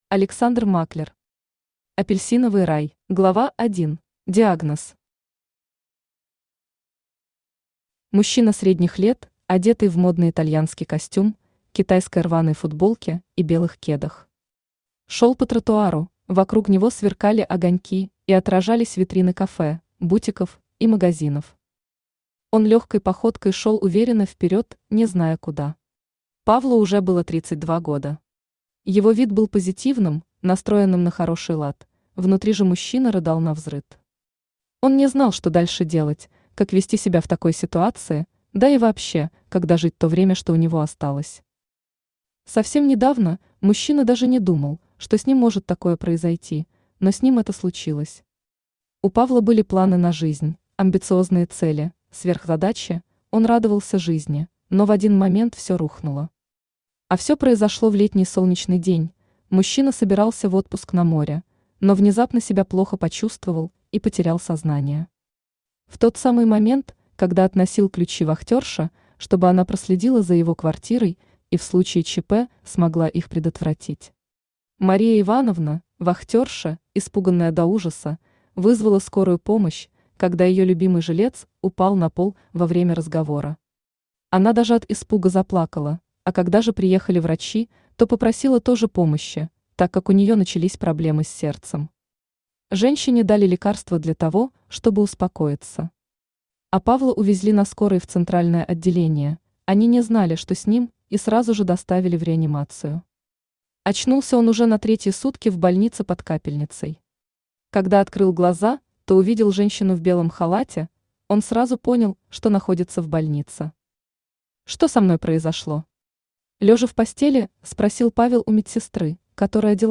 Аудиокнига Апельсиновый рай | Библиотека аудиокниг
Aудиокнига Апельсиновый рай Автор Александр Германович Маклер Читает аудиокнигу Авточтец ЛитРес.